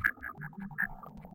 Grabcrab_idle3.ogg